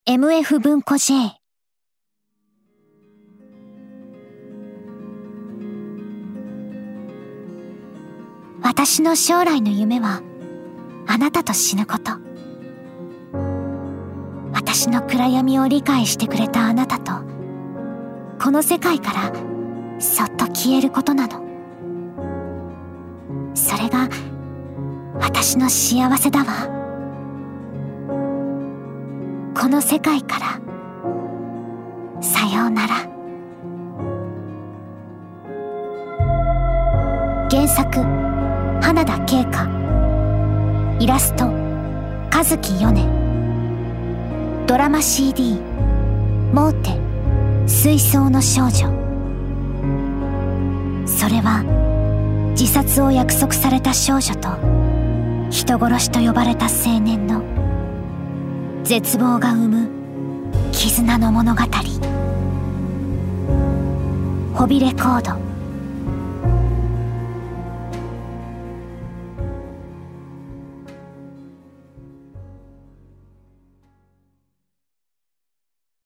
音声CM公開中！